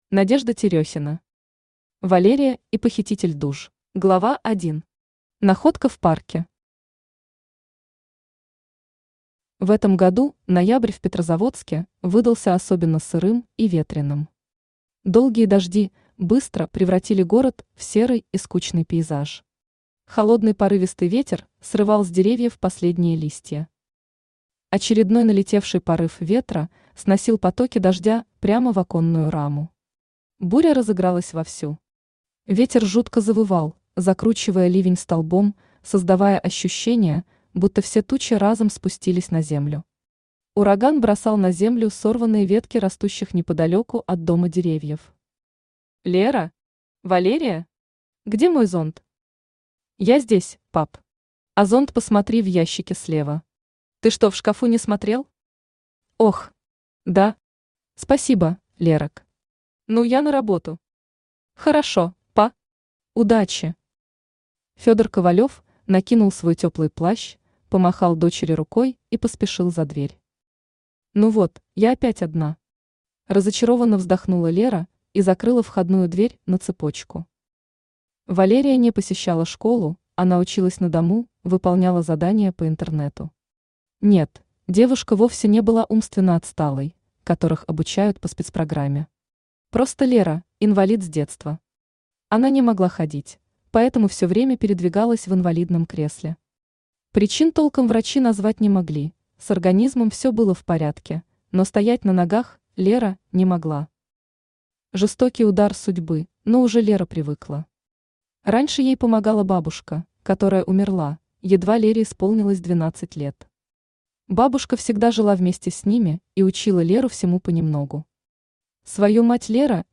Аудиокнига Валерия и похититель душ | Библиотека аудиокниг
Aудиокнига Валерия и похититель душ Автор Надежда Терехина Читает аудиокнигу Авточтец ЛитРес.